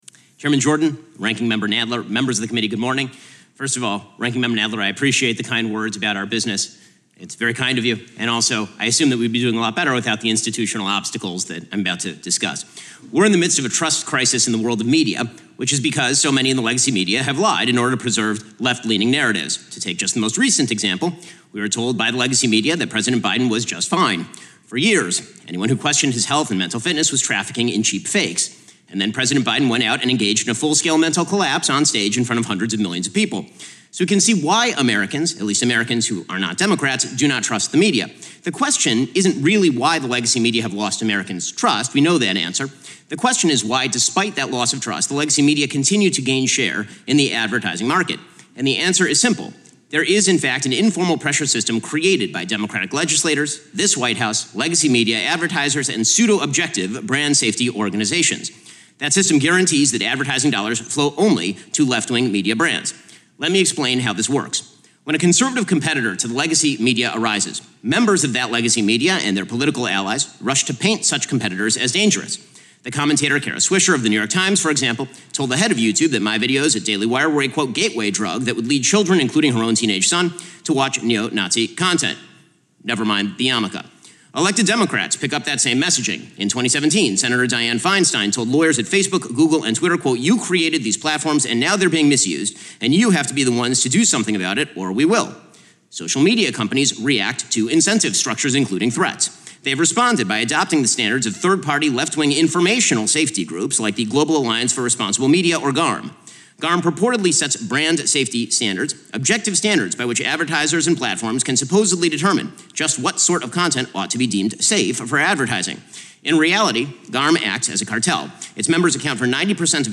Opening Remarks at a House Judiciary Committee Hearing on Brand Control of Online Speech
delivered 9 July 2024, Washington, D.C.
Audio Note: AR-XE = American Rhetoric Extreme Enhancement